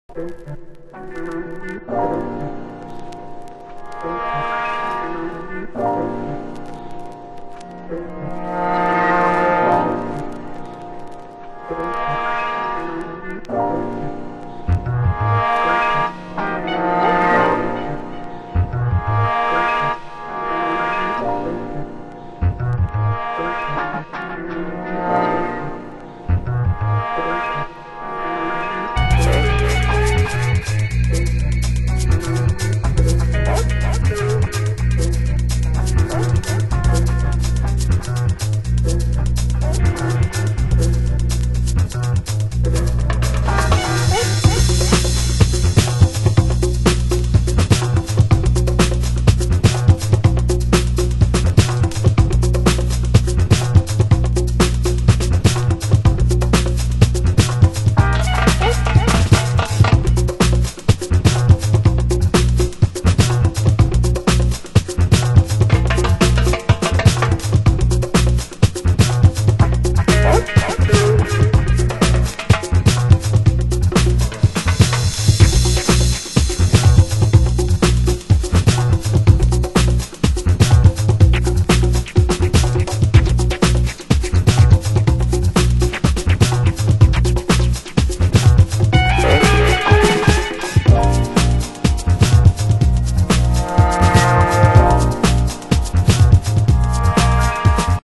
LATIN/DUB/BREAK！　　盤質：少しチリノイズ有